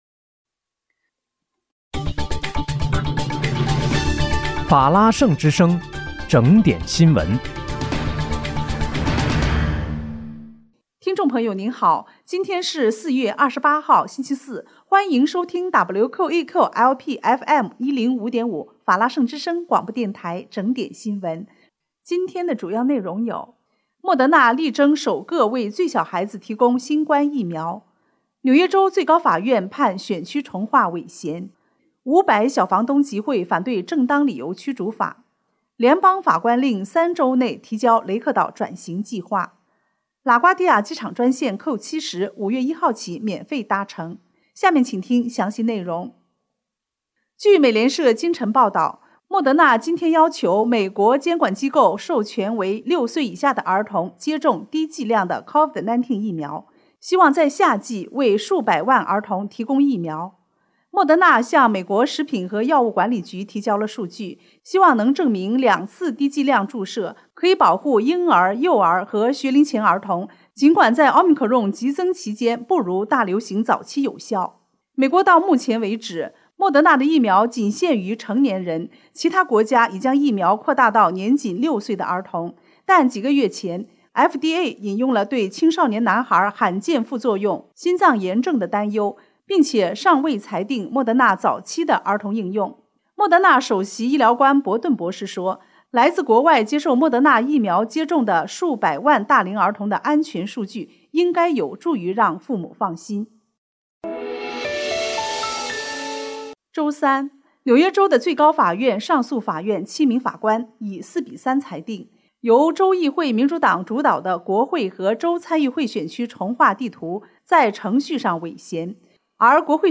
4月28日（星期四）纽约整点新闻
听众朋友您好！今天是4月28号，星期四，欢迎收听WQEQ-LP FM105.5法拉盛之声广播电台整点新闻。